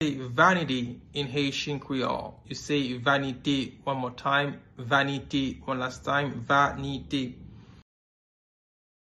Pronunciation:
31.How-to-say-Vanity-in-Haitian-Creole-–-Vanite-pronunciation.mp3